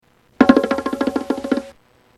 Bongo Roll